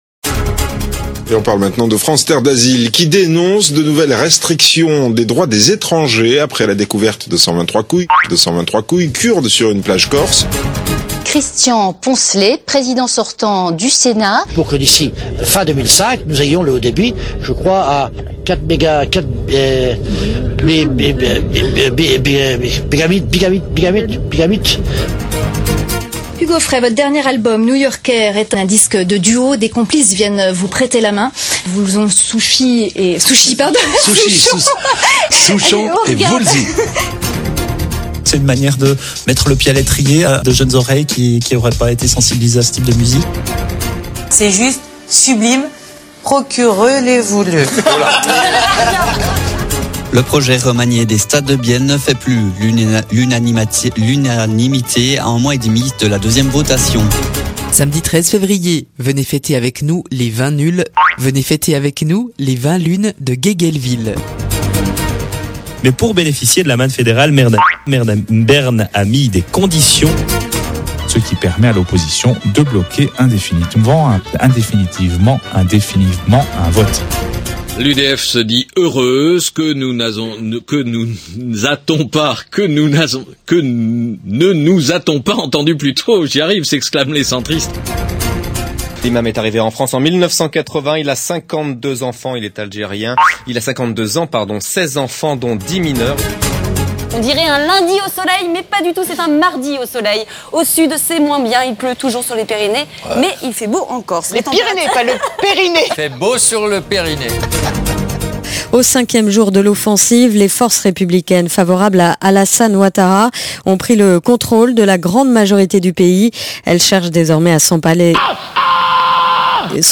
La foire aux bafouillages, lapsus, contrepèteries, néologismes, avec, en sus (et pour le même prix) quelques gaffes et révélations d'ordre privé...
Origine : TV et archives radio.